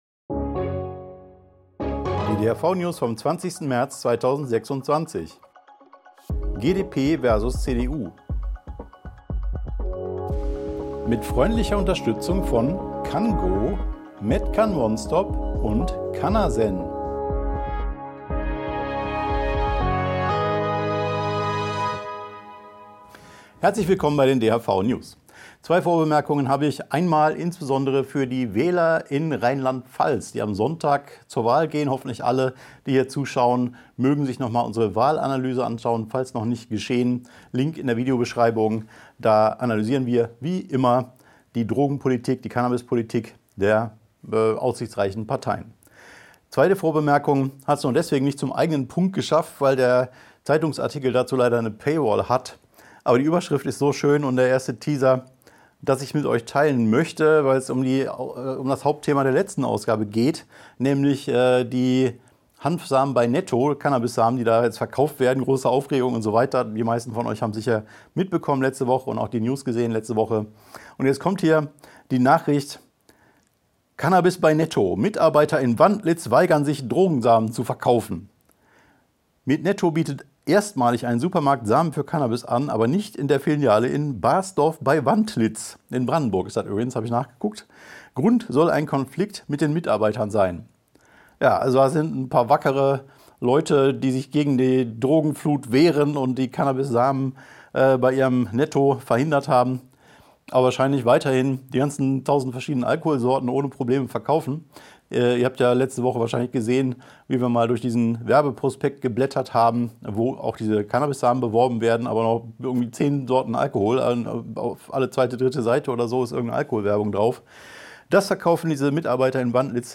Video-News
DHV-News # 502 Die Hanfverband-Videonews vom 20.03.2026 Die Tonspur der Sendung steht als Audio-Podcast am Ende dieser Nachricht zum downloaden oder direkt hören zur Verfügung.